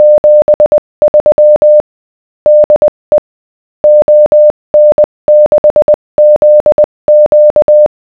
The GNU Octave program below generates a sound file (.wav) with the morse code representing a given text.
no smoothing
Lots of "clicks"...
MorseSoundFileGenerator_0.wav